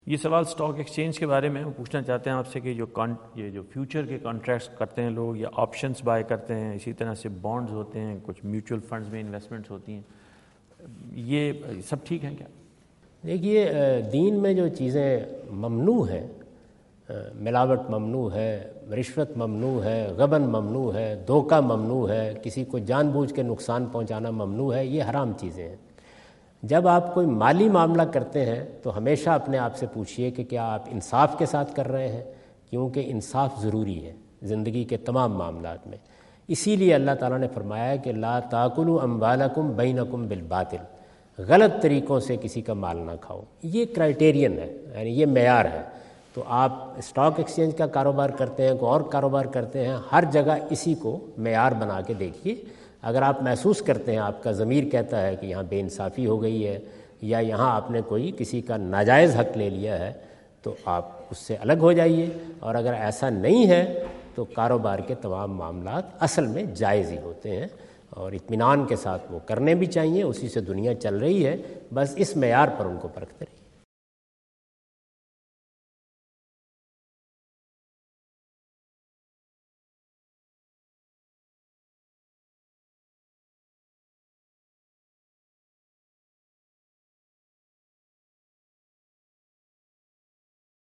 Category: English Subtitled / Questions_Answers /
Javed Ahmad Ghamidi answer the question about "Earning from Stock Exchange" asked at North Brunswick High School, New Jersey on September 29,2017.